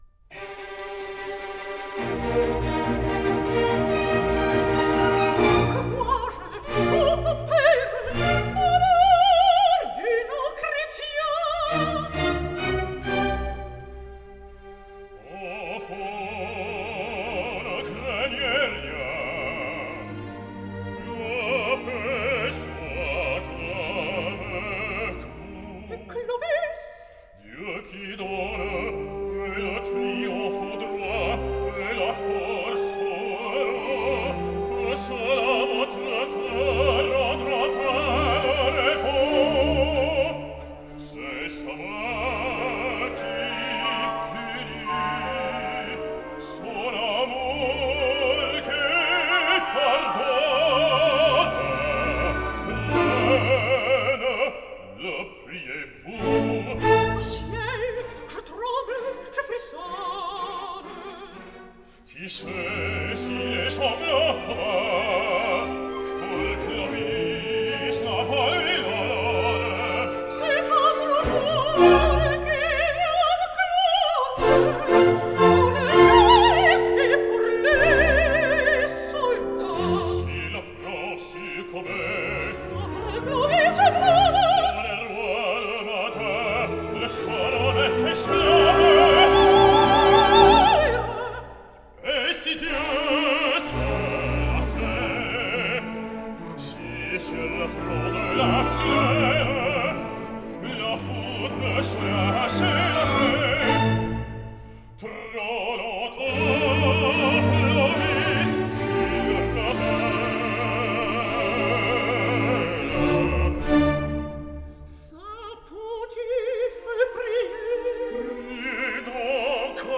cantata
Here’s the Scene 2 duet between Clotilde and Rémy